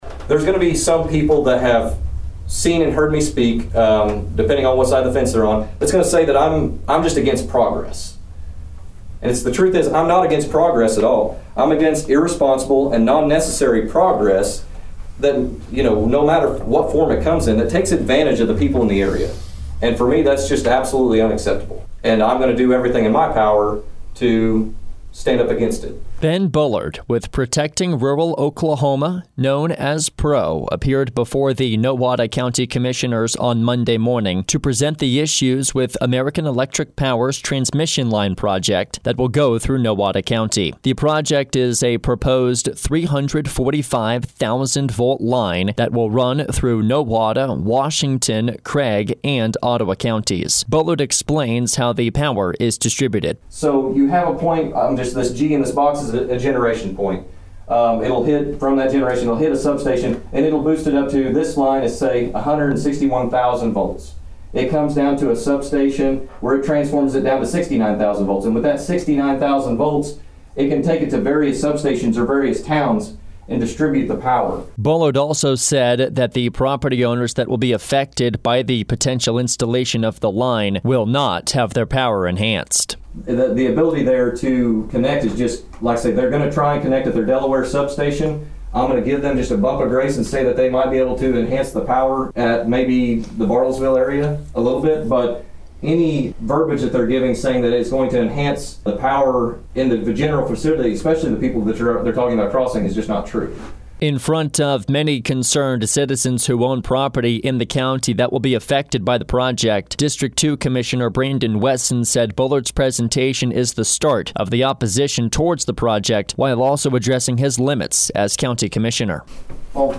CLICK HERE FOR THE FULL VOICER.